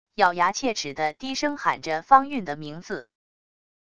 咬牙切齿地低声喊着方运的名字wav音频